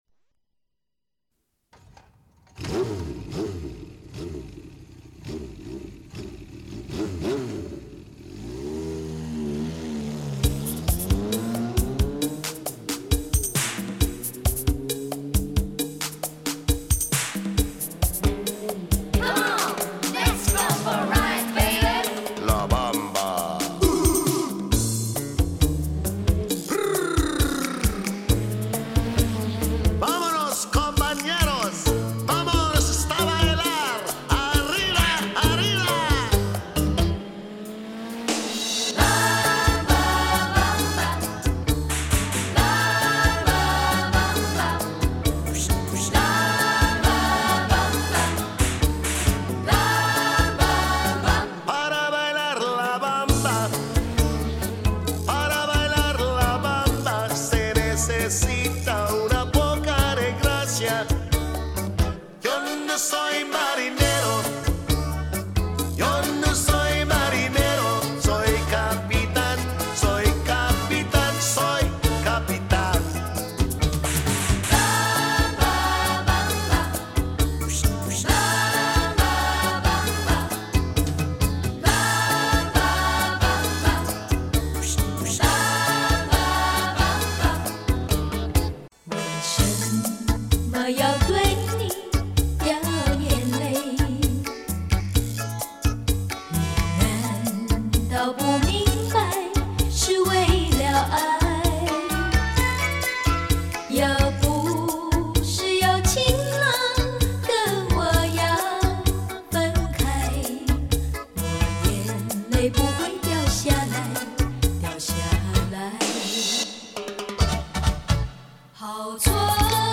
不自觉地，我的心和两脚会摇着 Cha Cha 的舞步。
【虽然是分为36段，实际上应该按曲号顺序连在一起串烧播放】